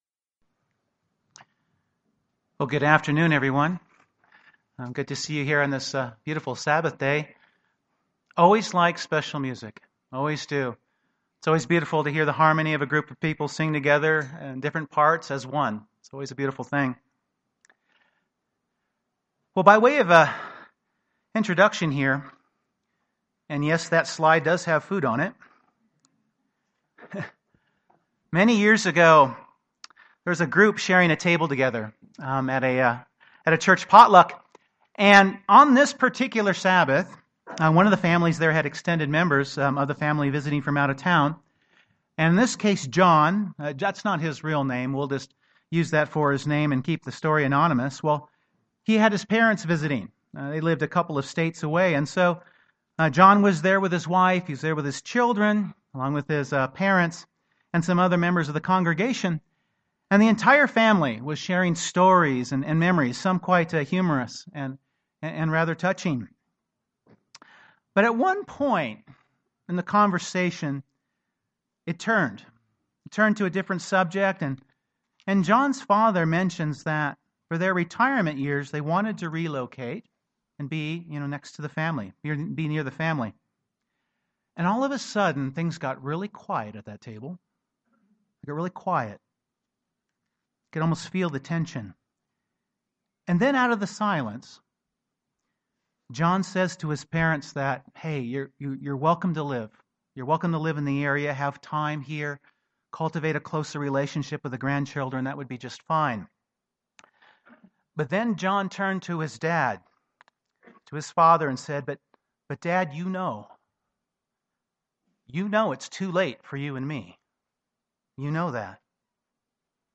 Given in Denver, CO